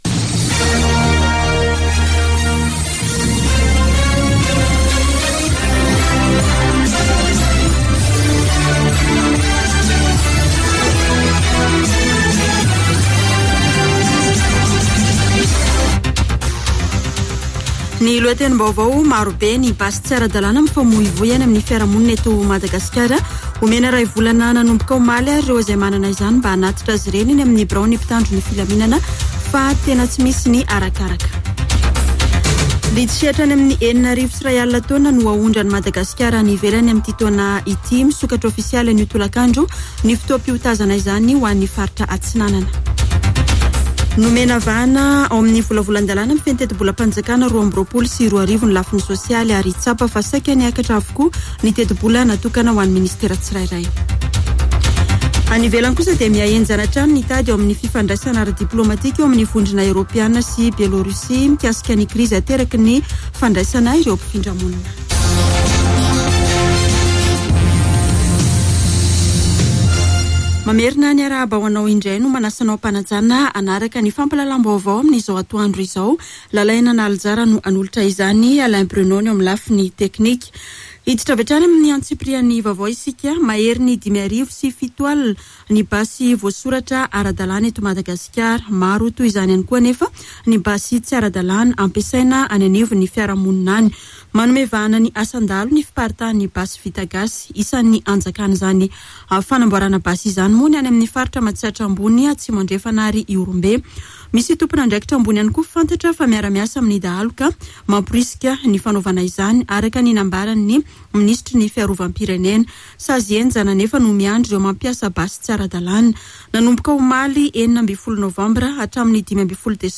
[Vaovao antoandro] Alarobia 17 novambra 2021